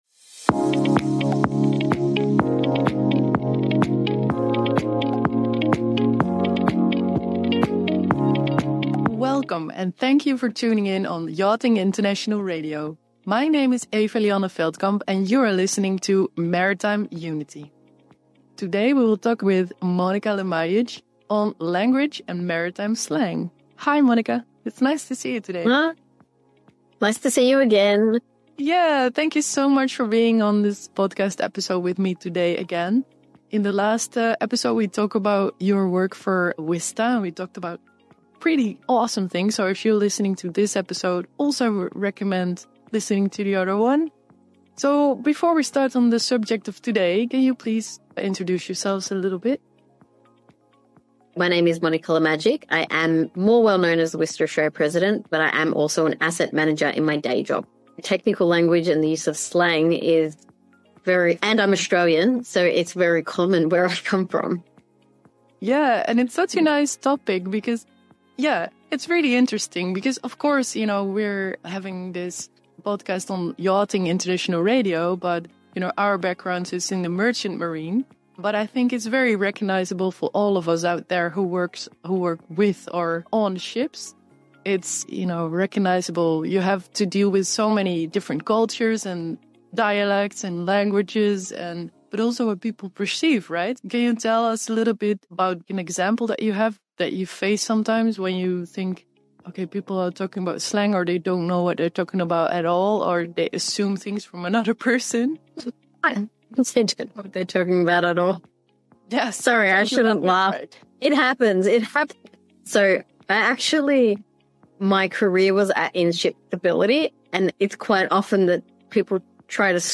Don't miss this engaging conversation and share your own language-related experiences in the comments!